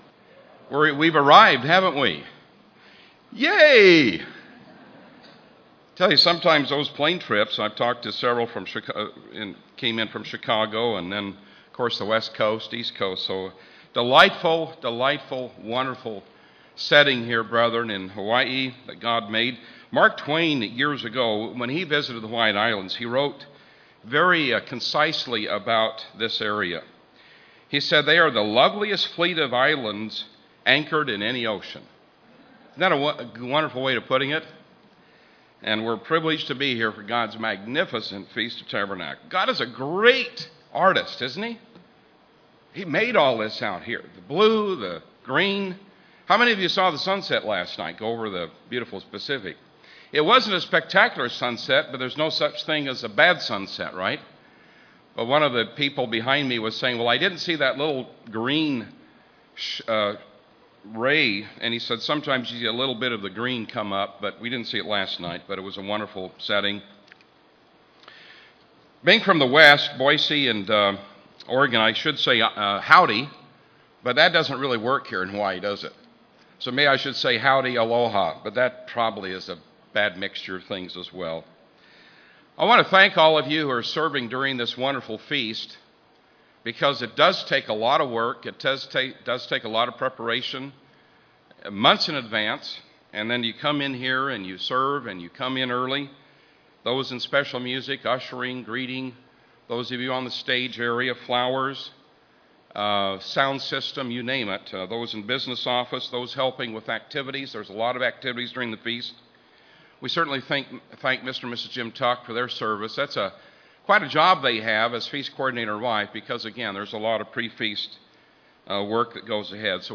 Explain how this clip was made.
This sermon was given at the Maui, Hawaii 2013 Feast site.